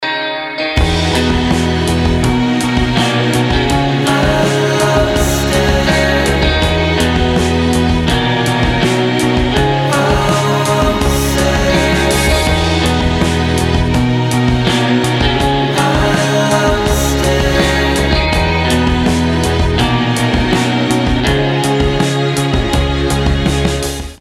• Качество: 320, Stereo
мужской голос
спокойные
indie rock
surf rock